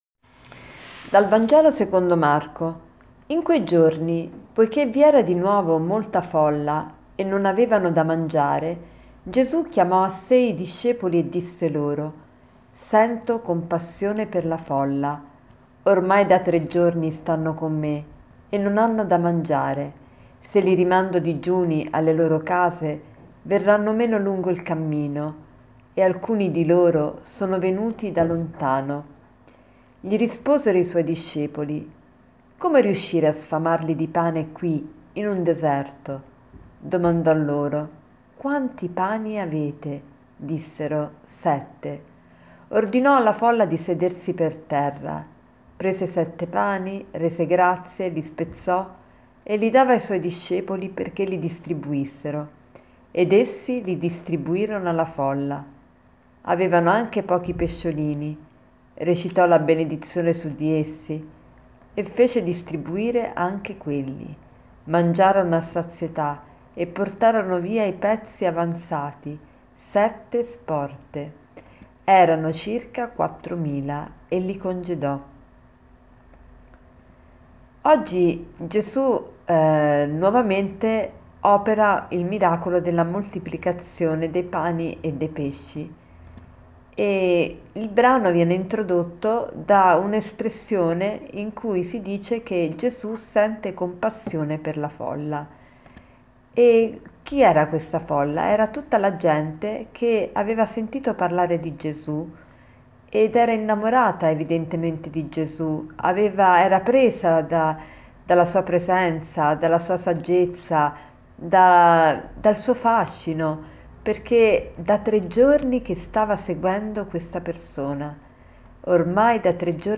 Commento al vangelo